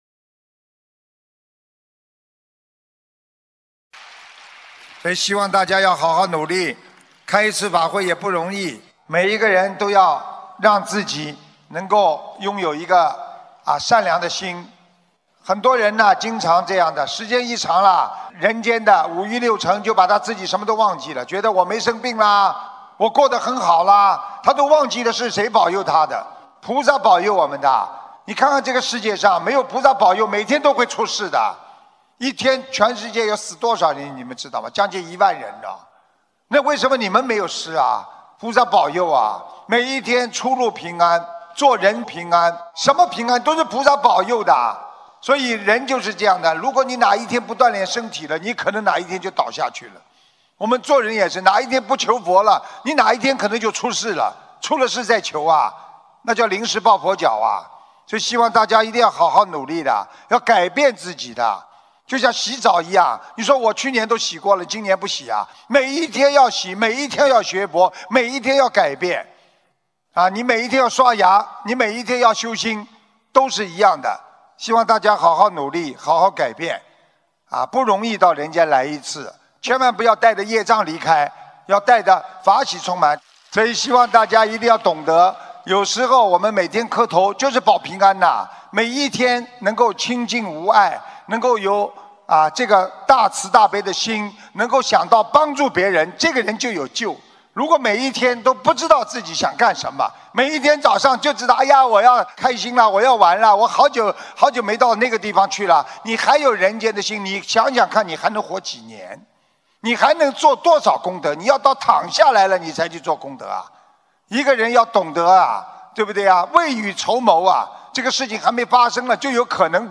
2019年12月8日墨尔本法会结束语-经典感人开示节选